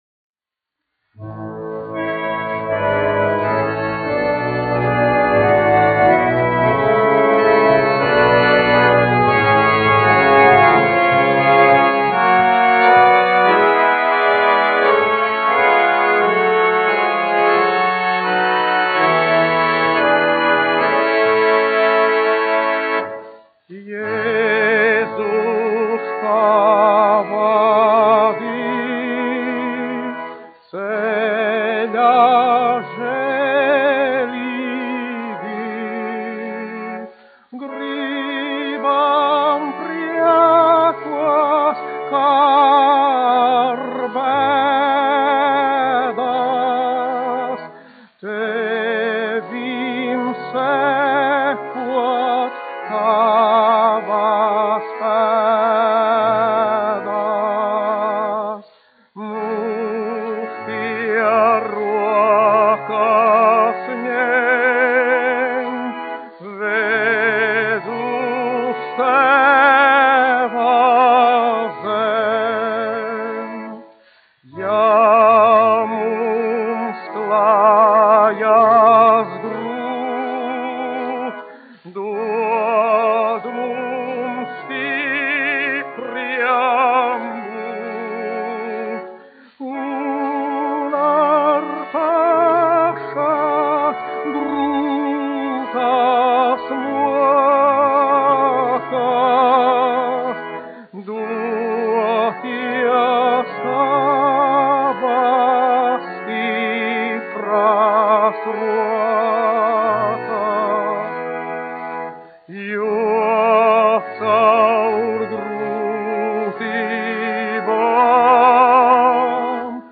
Jēzus pavadi : korālis
dziedātājs
1 skpl. : analogs, 78 apgr/min, mono ; 25 cm
Garīgās dziesmas ar ērģelēm
Latvijas vēsturiskie šellaka skaņuplašu ieraksti (Kolekcija)